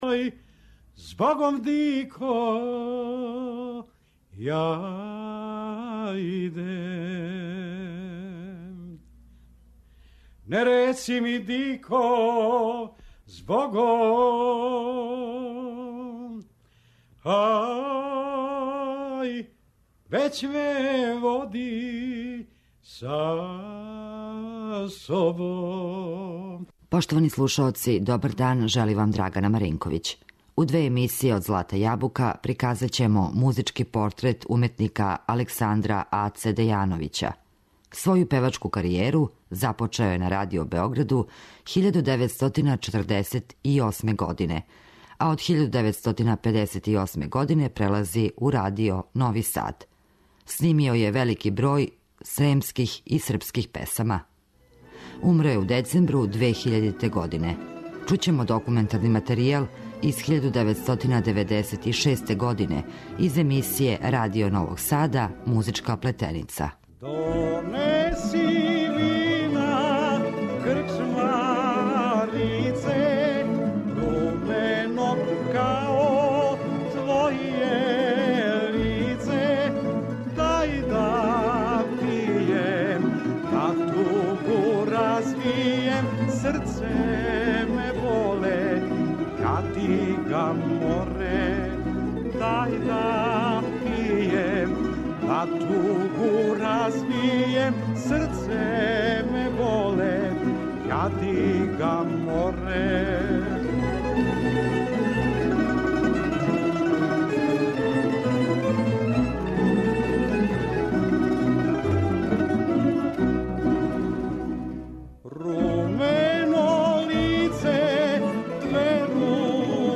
Снимио је велики број војвођанских песама за тонски архив.